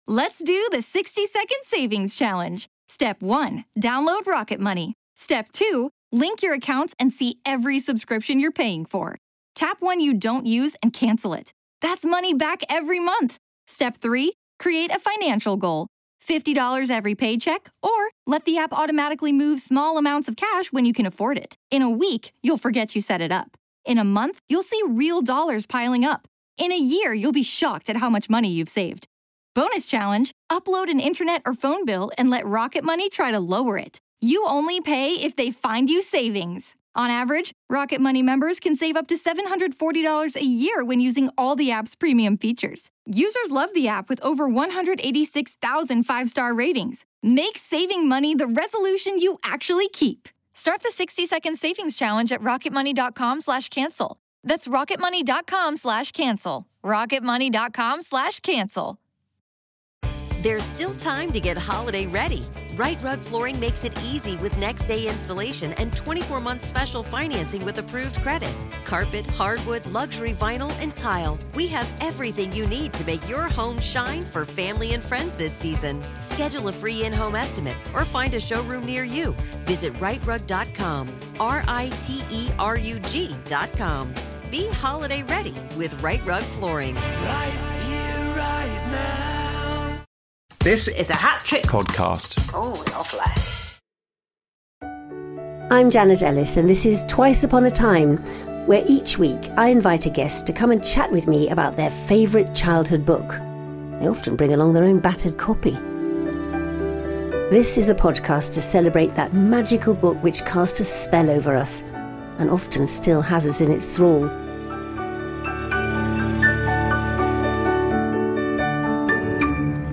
Recorded live at the Chiswick Festival, Phyllis Logan (Secrets and Lies, Lovejoy, Downton Abbey) joins Janet to discuss her favourite childhood book Alison's Spy Adventure - a rollicking tale of espionage set in Scotland with Alison and her childhood friend solving the great mystery before the adults do.